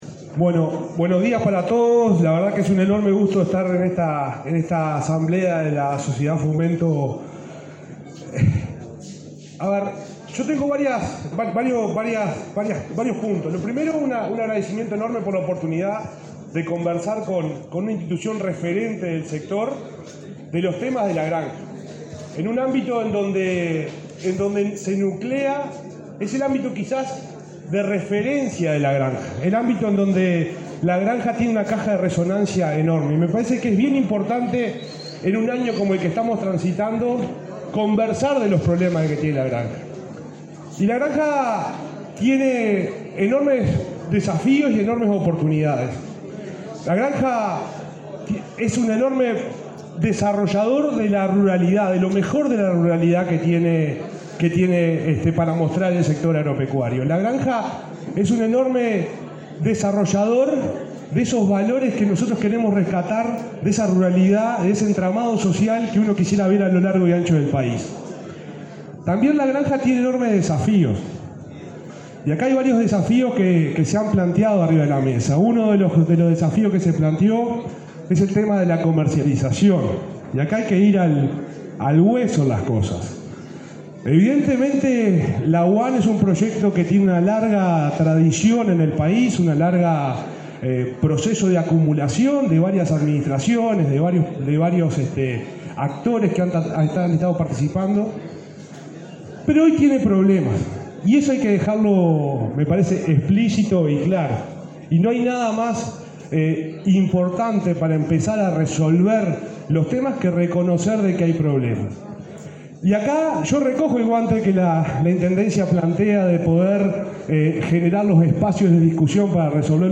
Disertación del subsecretario del MGAP, Juan Ignacio Buffa
Disertación del subsecretario del MGAP, Juan Ignacio Buffa 02/06/2024 Compartir Facebook X Copiar enlace WhatsApp LinkedIn El presidente de la República, Luis Lacalle Pou, participó, este 2 de junio, en la celebración de la 93.ª asamblea anual de la Sociedad de Fomento y Defensa Agraria. En el acto, disertó el subsecretario del Ministerio de Ganadería, Agricultura y Pesca (MGAP), Juan Ignacio Buffa.